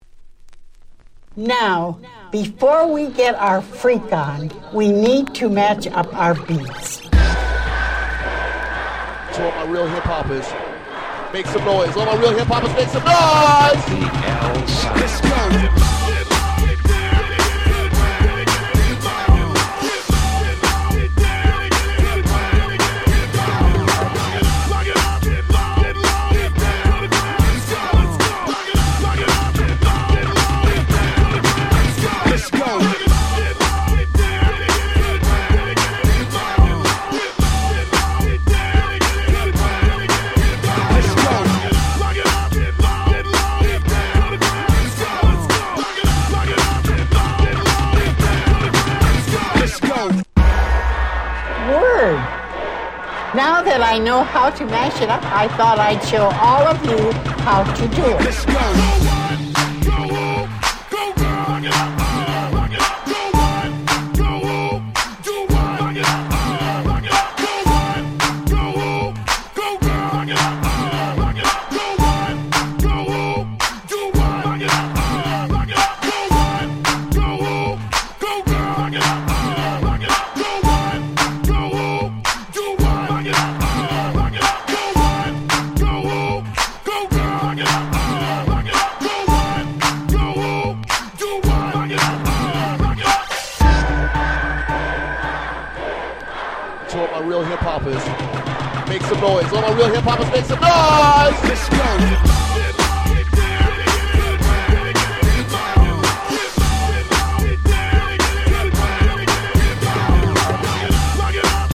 07' Nice Party Tracks / Mash Up !!
00's Hip Hop R&B